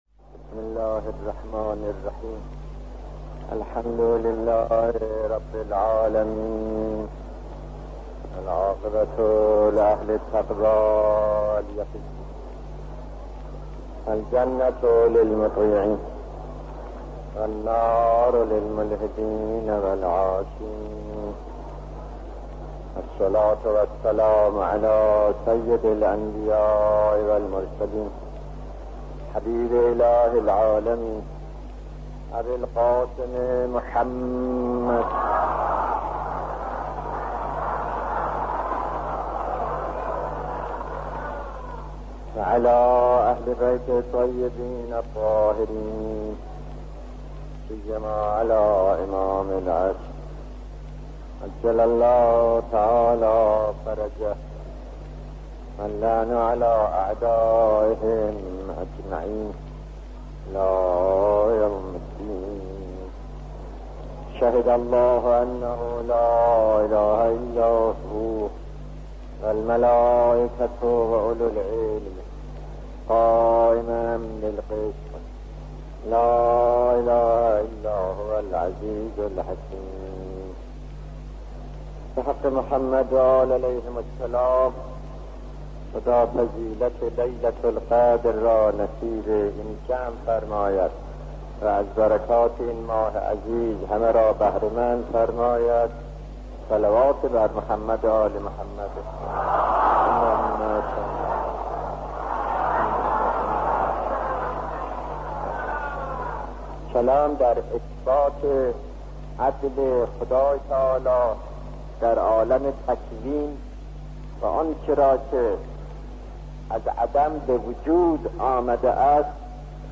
سخنرانی شهید آیت الله دستغیب، عدل الهی در مسئله تکوین